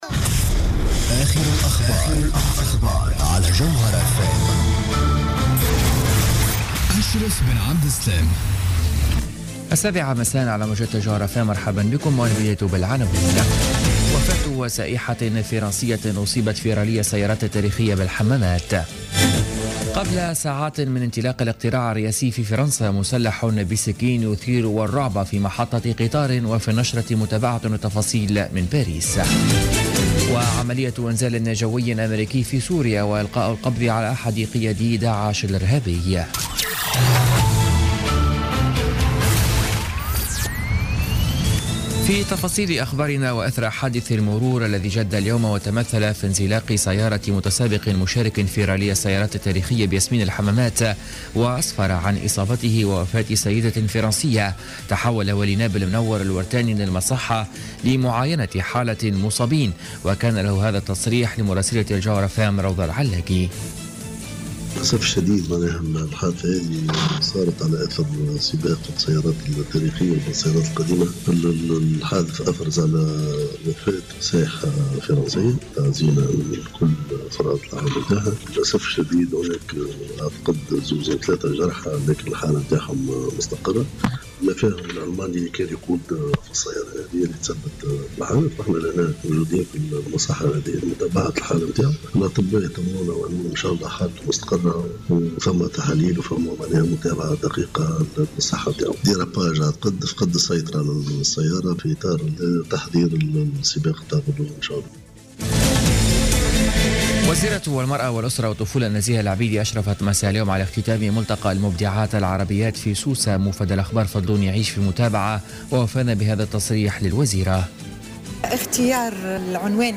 نشرة أخبار السابعة مساء ليوم السبت 22 أفريل 2017